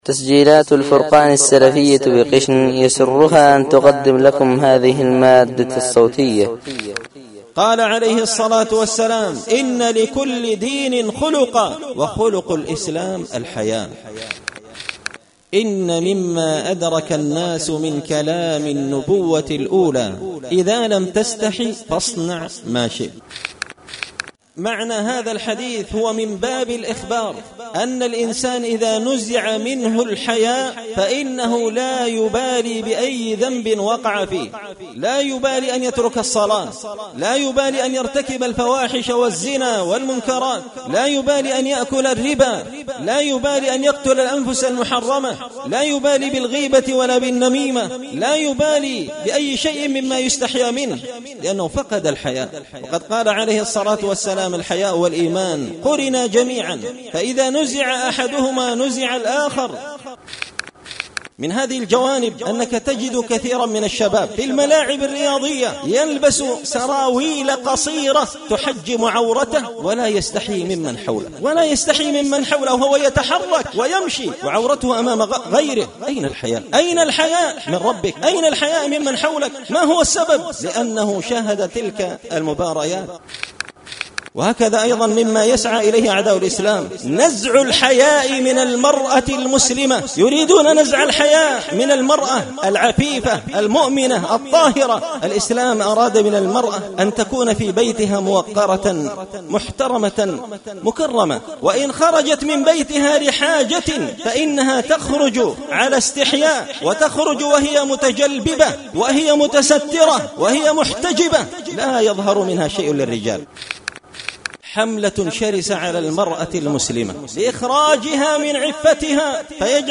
مقتطف من خطبة جمعة لكل دين خلقا وخلق الإسلام الحياء
دار الحديث بمسجد الفرقان ـ قشن ـ المهرة ـ اليمن
مقتطف_من_خطبة_لكل_دين_خلقا_وخلق_الإسلام_الحياء.mp3